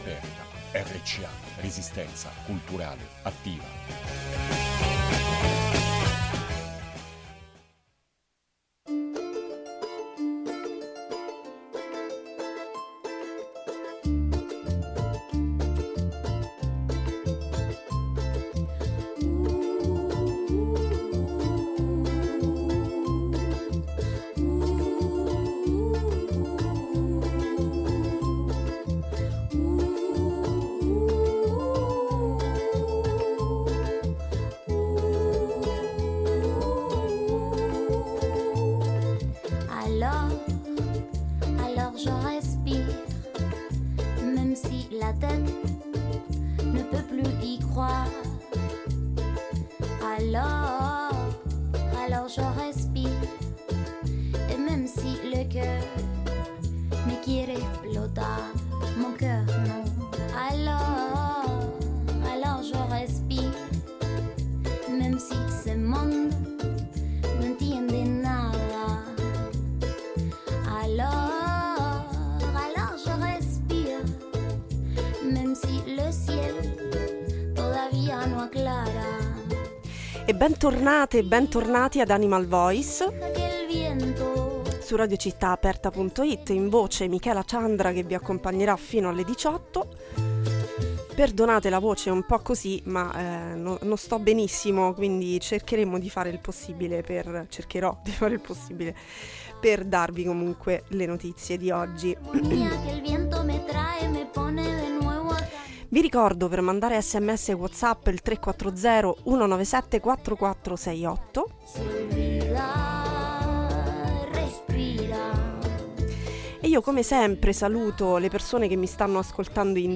In questa puntata: Notizie dal mondo Un viaggio di 40 ore per la giraffa Benito Intervista